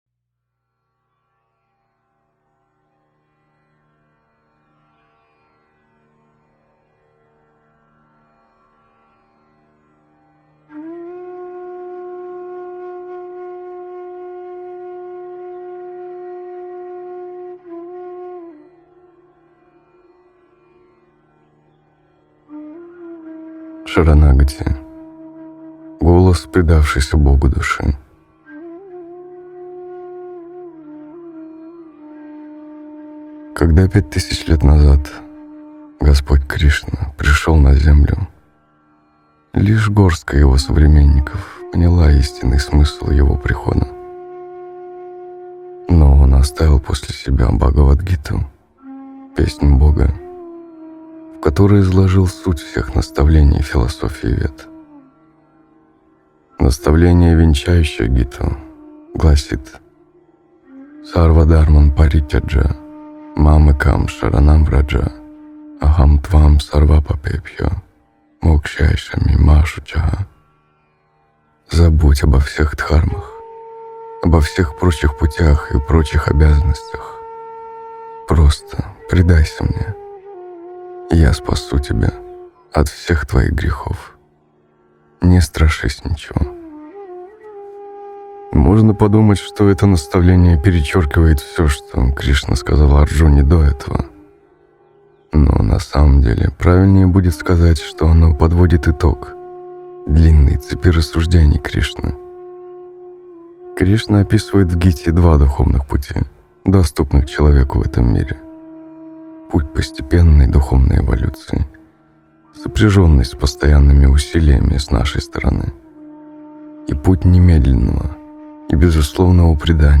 "Шаранагати" (аудио книга) – Лекции и книги Александра Хакимова